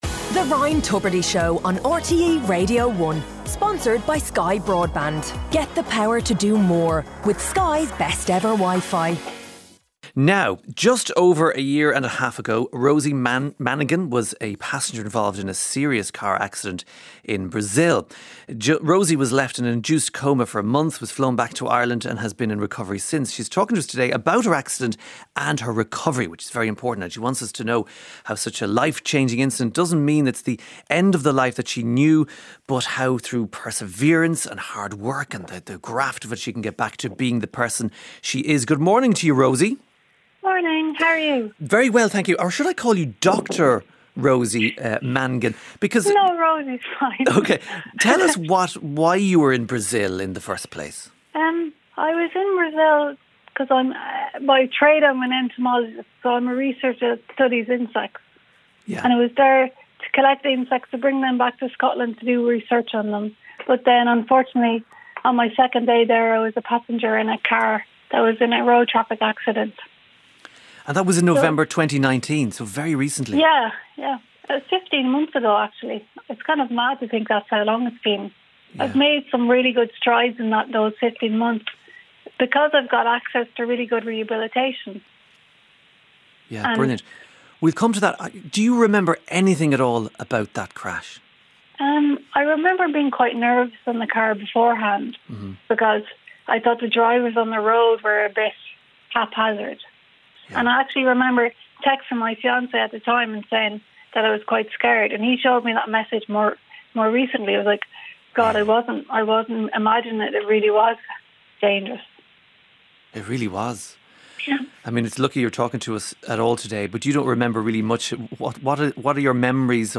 Play Rate Apps Listened List Bookmark Share Get this podcast via API From The Podcast The Ryan Tubridy Show 2 Highlights from the daily radio show with Ryan Tubridy including big interviews, the daily news round-up and the 'Best of the Week Podcast' every Friday. Listen live Monday to Friday at 9am on RTÉ Radio 1.